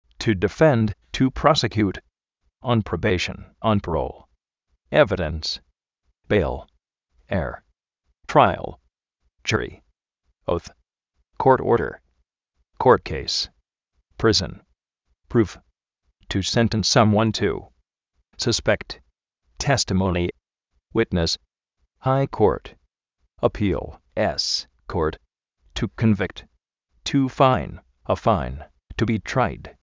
tu difénd, tu prósekiut
on probéishn, on paról
tráial